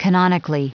Prononciation du mot canonically en anglais (fichier audio)
canonically.wav